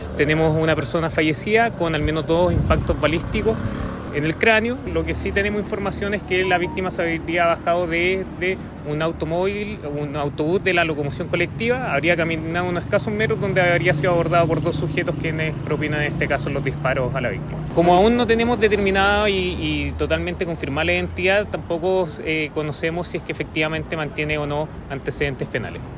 Así lo confirmó el fiscal ECOH, Jonathan Coloma, quien añadió que al no estar clarificada la identidad de la víctima, se desconoce si mantenía o no antecedentes penales previos.